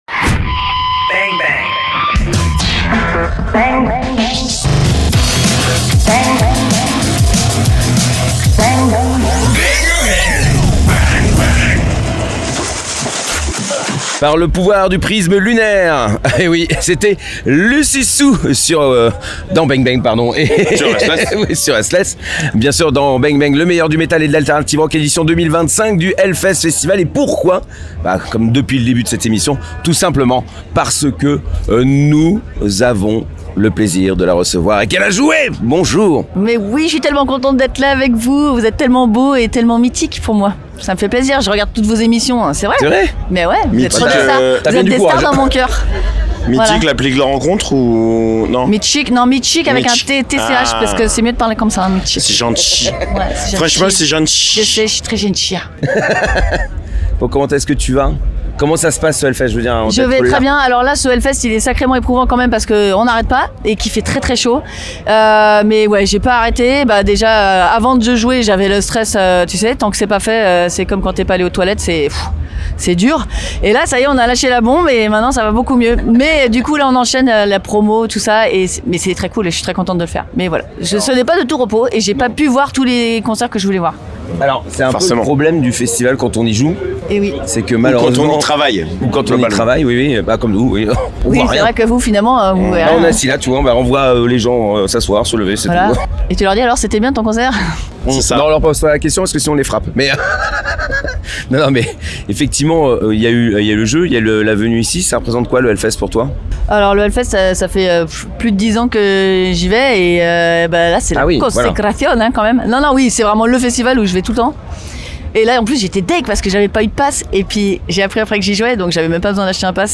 Et nous avons fait 23 interviews !